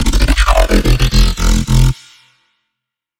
键盘3
描述：雅马哈yz35合成器不是midi合成器
标签： 150 bpm Pop Loops Synth Loops 551.29 KB wav Key : Unknown Magix Music Maker
声道立体声